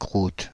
Crouttes (French pronunciation: [kʁut]
Fr-Crouttes.ogg.mp3